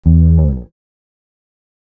E FALL OFF.wav